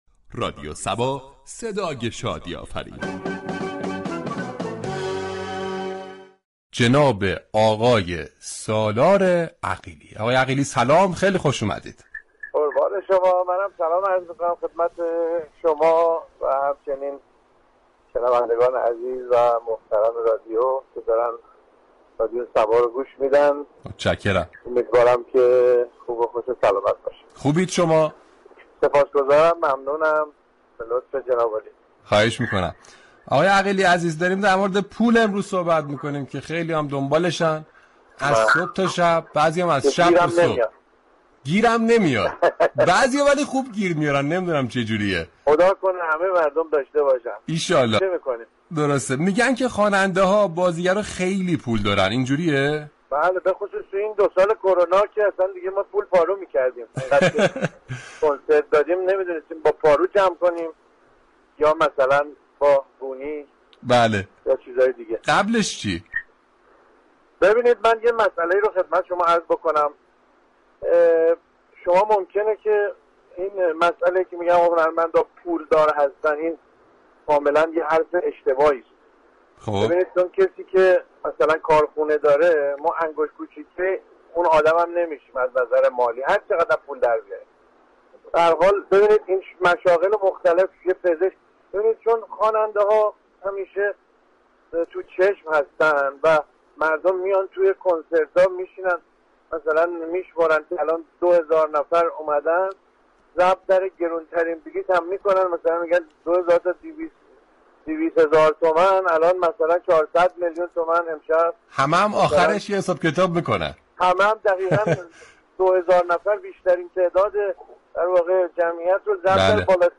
گفتگو عصر صبا با سالار عقیلی
رنامه زنده "عصر صبا " در قالب مجله عصرگاهی با بیان موضوعاتی اجتماعی و حقوق شهروندی را بیان می كند .